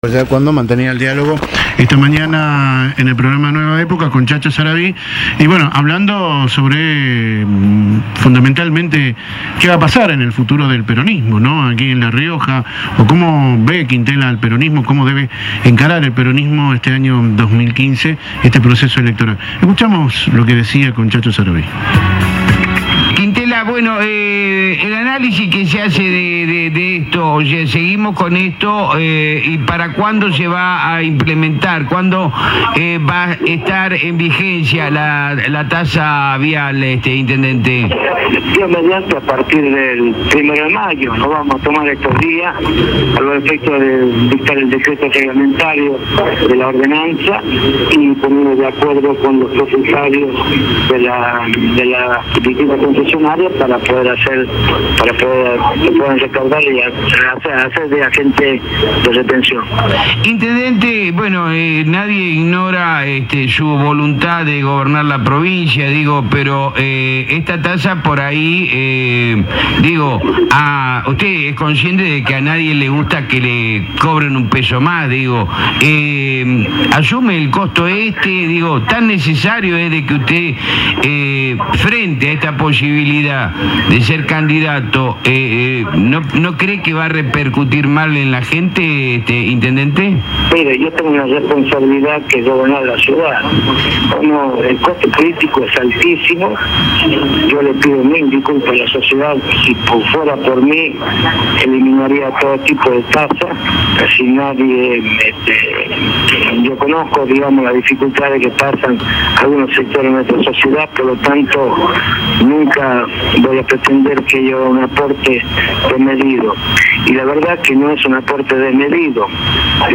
Quintela no descartó por Radio Libertad tomar un café con el gobernador